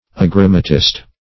Search Result for " agrammatist" : The Collaborative International Dictionary of English v.0.48: Agrammatist \A*gram"ma*tist\, n. [Gr.